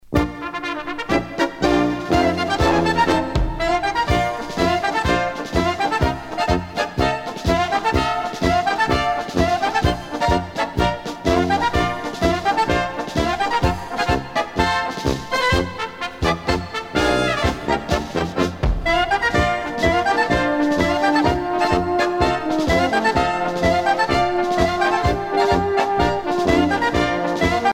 à marcher
Pièce musicale éditée